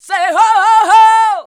SAY 3.wav